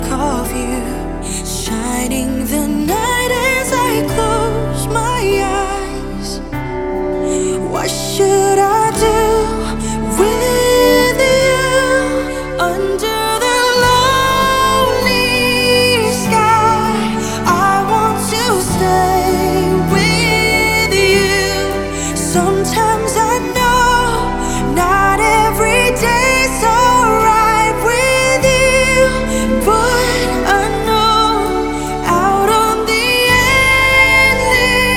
Жанр: Поп музыка / Соундтрэки
TV Soundtrack, Soundtrack, Pop, K-Pop